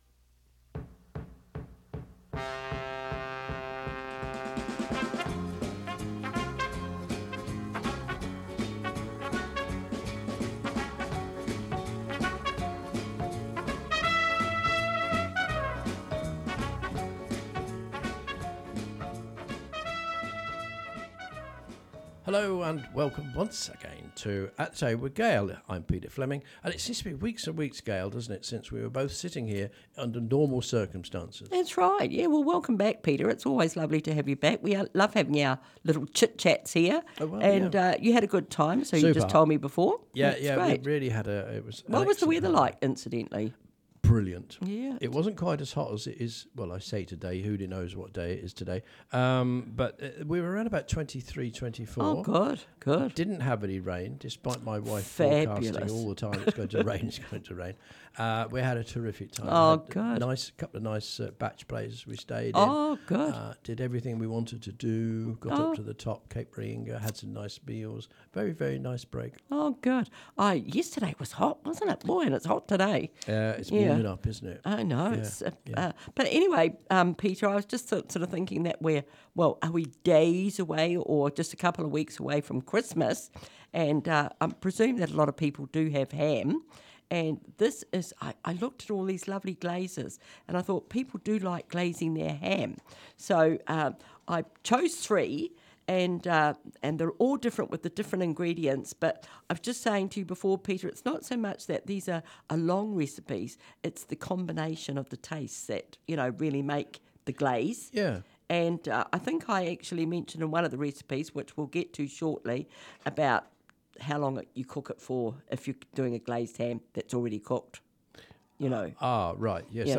Always delivered with a bit of a laugh, tune in to be inspired to get cooking in your kitchen.